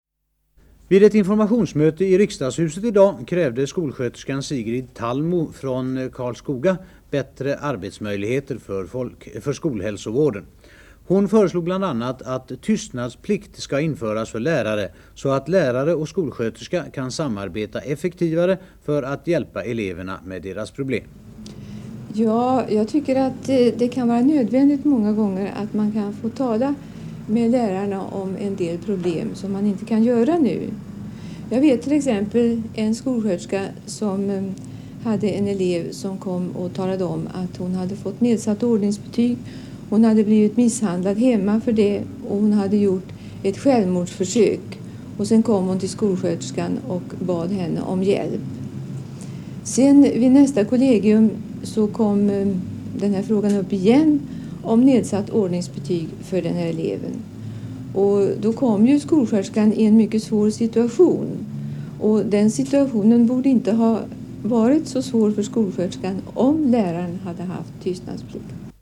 kort ljudklipp ur TV-Aktuellt samma kväll.